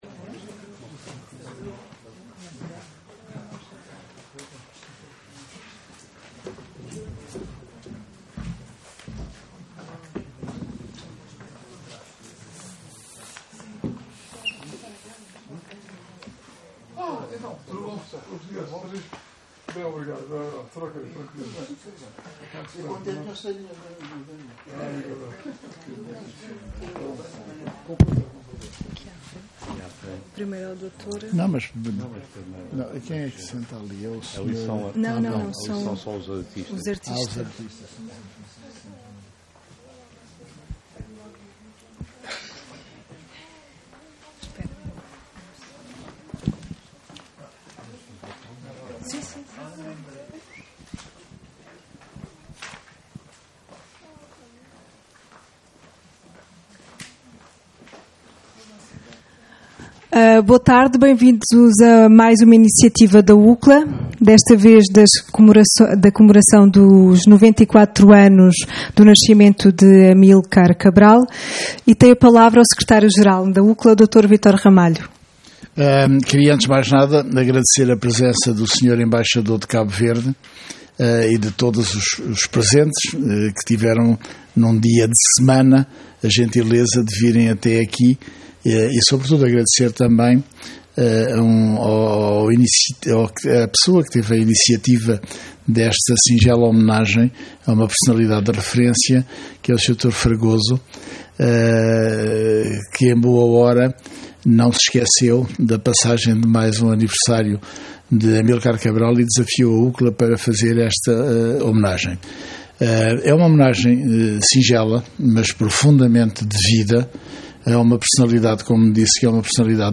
Por ocasião da celebração dos 94 anos do nascimento de Amílcar Cabral, a UCCLA e o Grupo Cénico TCHON DI KAUBERDI organizaram, no dia 13 de setembro, na sede da UCCLA, um evento cultural para assinalar esta efeméride.